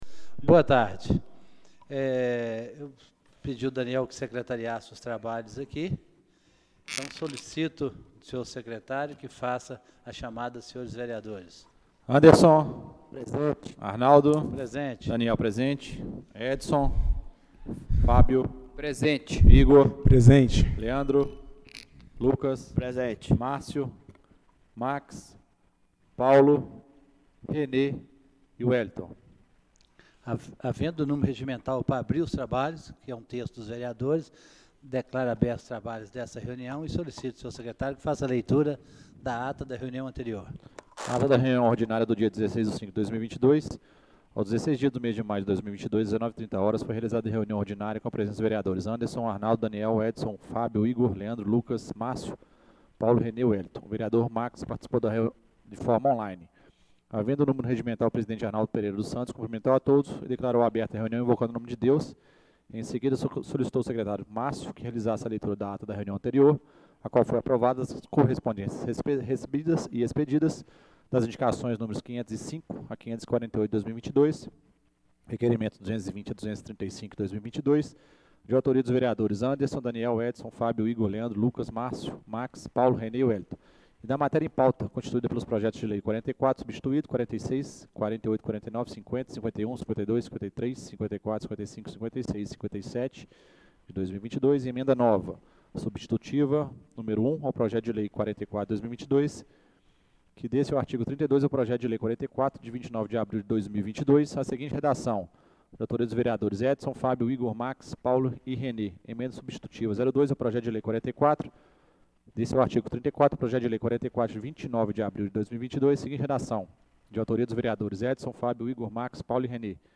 Reunião Extraordinária do dia 17/05/2022